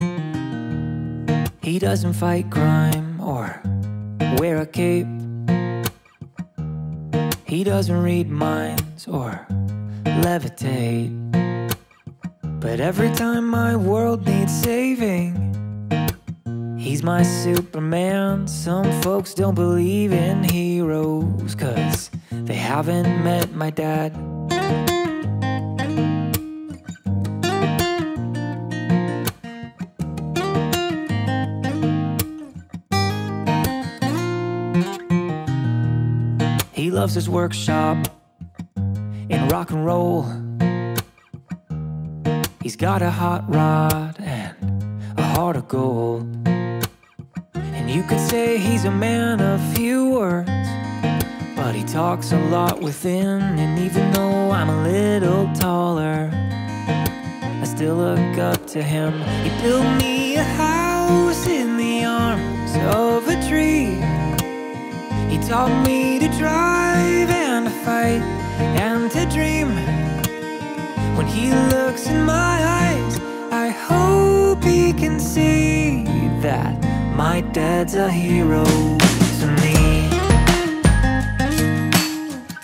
• Качество: 320, Stereo
гитара
мужской вокал
душевные
мелодичные
спокойные
баллада
легкий рок
поп-рок